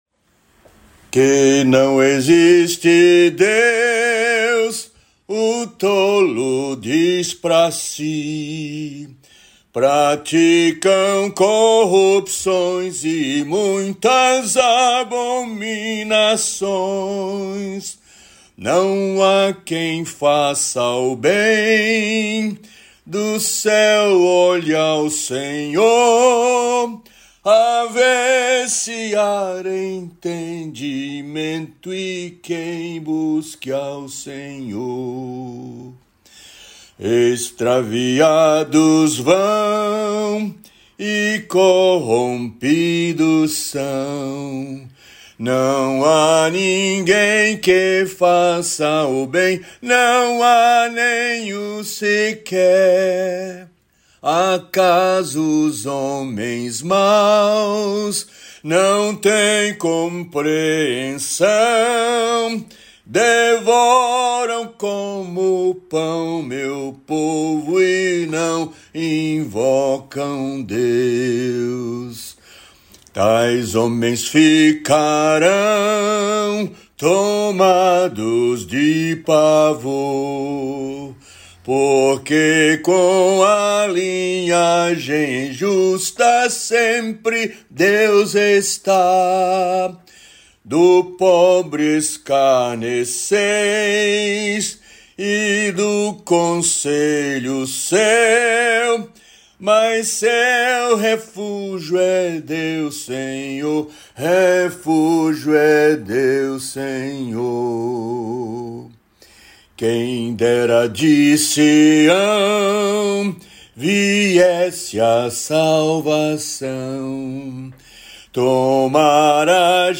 salmo_14B_cantado.mp3